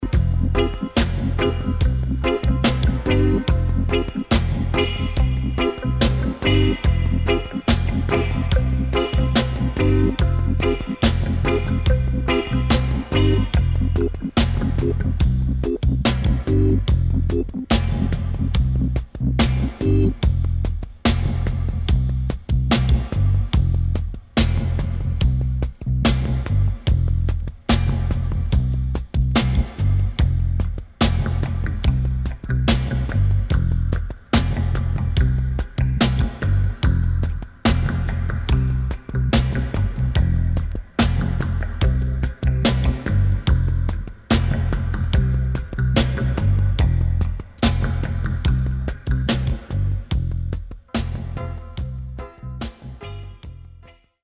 HOME > REISSUE [DANCEHALL]